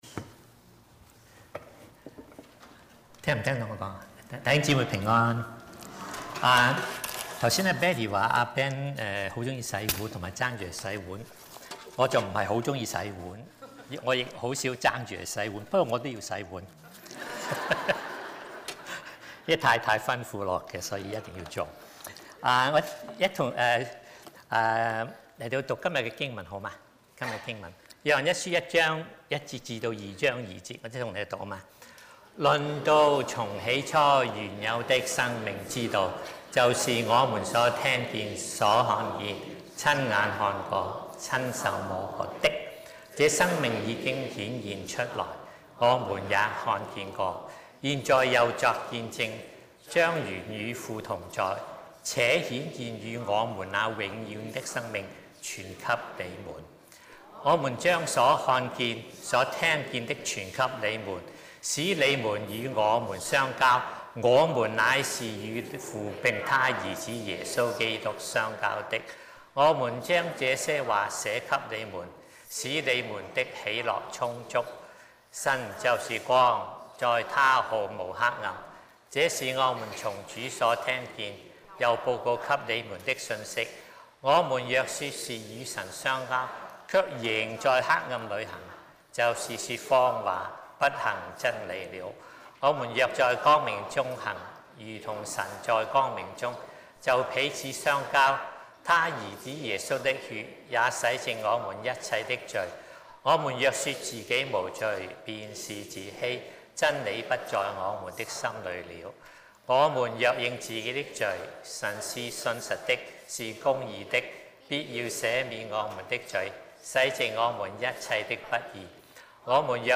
SERMONS | 講道 | Westwood Alliance Church